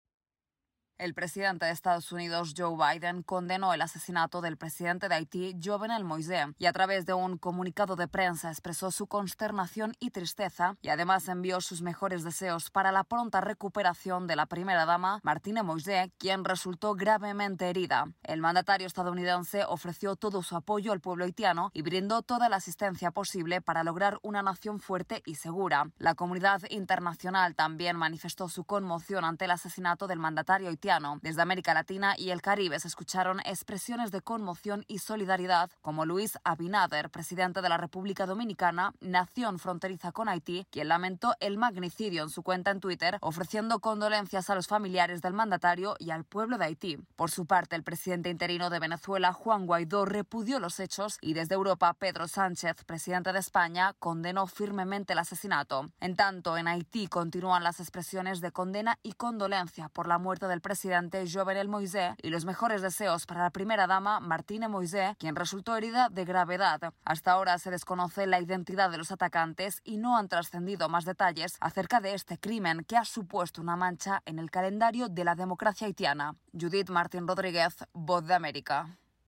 El presidente Joe Biden y otros líderes internacionales condenan el asesinato del presidente de Haití, Jovenel Moïse. Informa